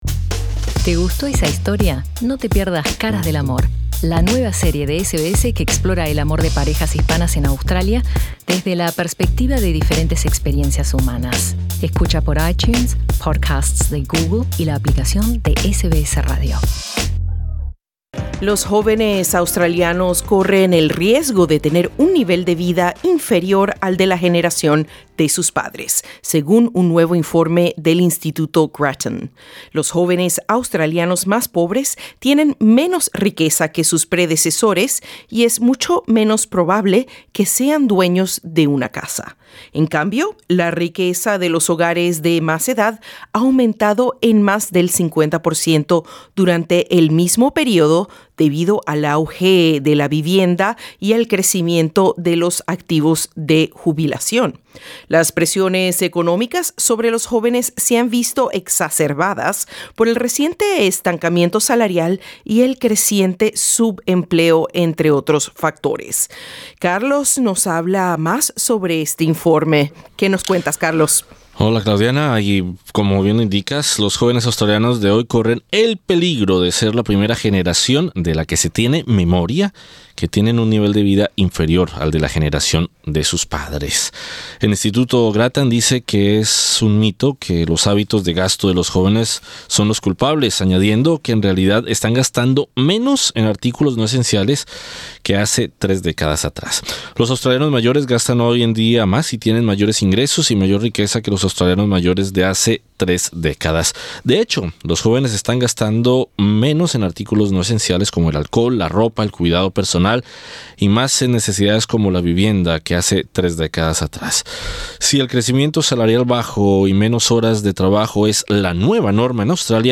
Entrevista con el economista en Sídney